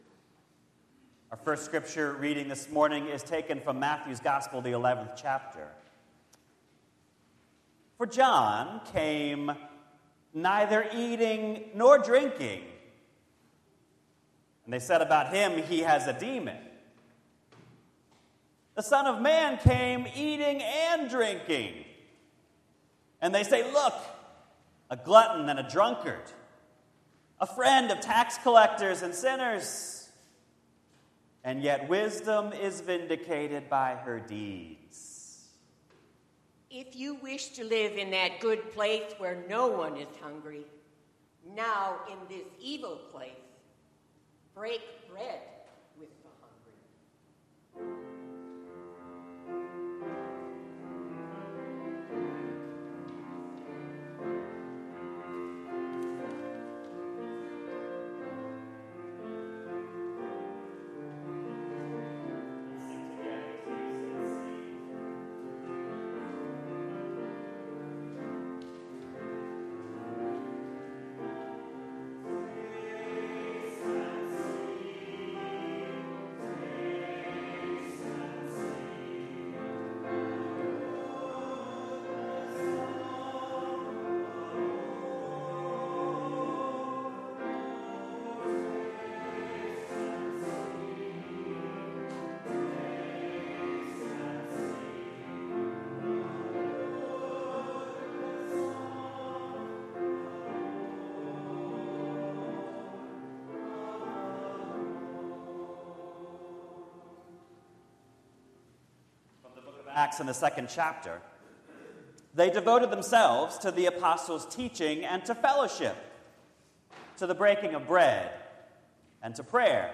NDPC Worship from 09/01/2019 Back to All Worships Every time a community gathers for worship, it is both a deeply conservative and a radically revolutionary act.